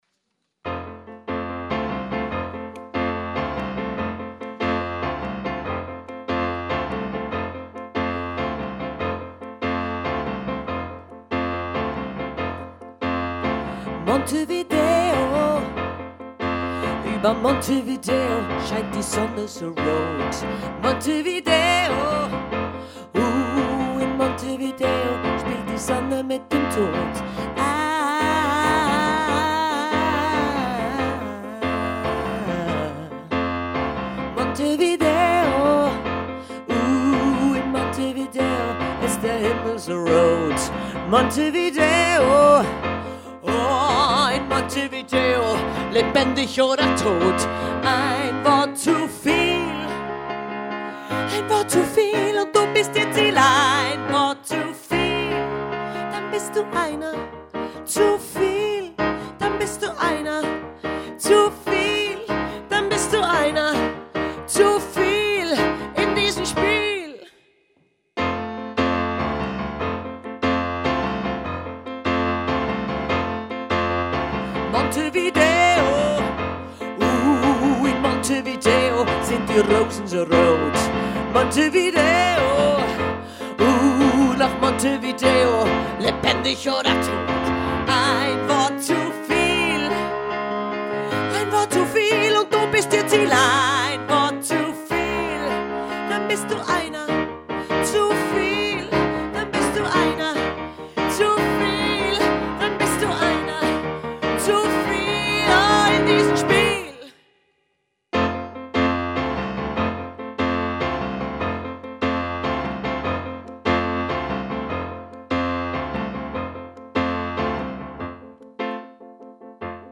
Gesang
Piano